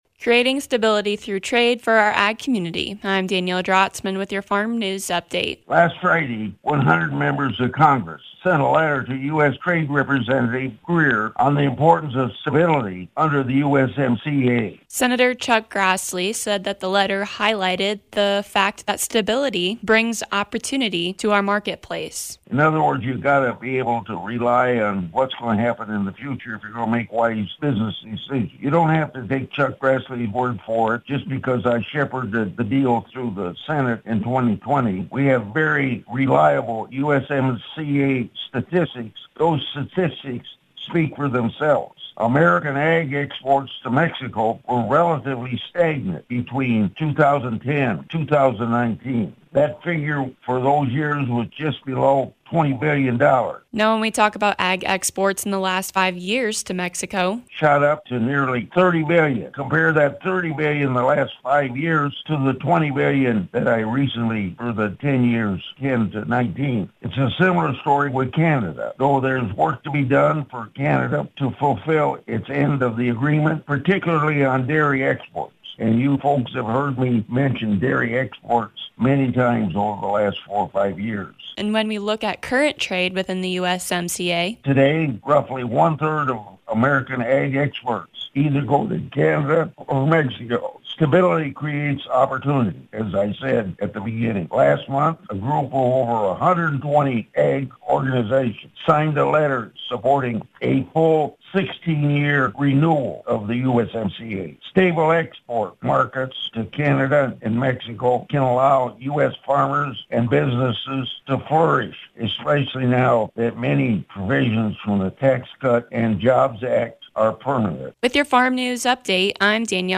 Senator Chuck Grassley talks about how certainty and stability in trade creates opportunities for our farmers.